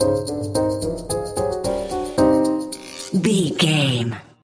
Aeolian/Minor
percussion
flute
orchestra
piano
silly
circus
goofy
comical
cheerful
perky
Light hearted
quirky